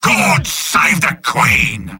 Robot-filtered lines from MvM. This is an audio clip from the game Team Fortress 2 .
{{AudioTF2}} Category:Sniper Robot audio responses You cannot overwrite this file.
Sniper_mvm_battlecry05.mp3